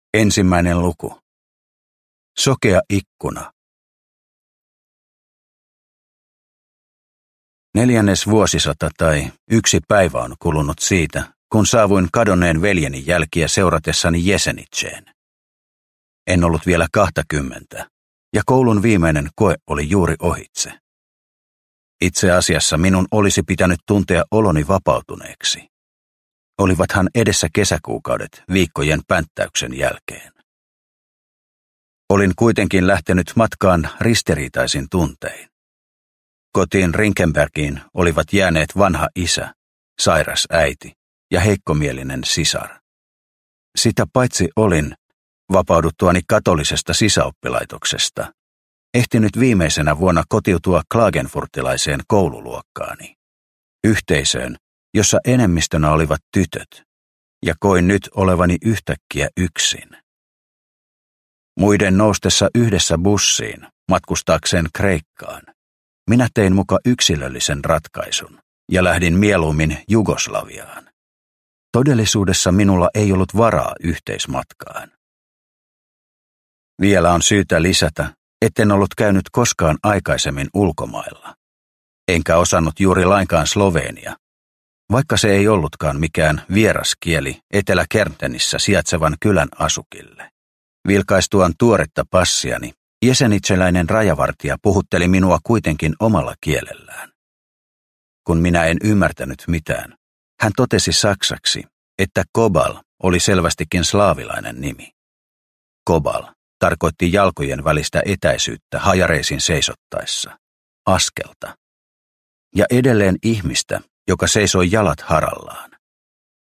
Toiston pysyvyys – Ljudbok – Laddas ner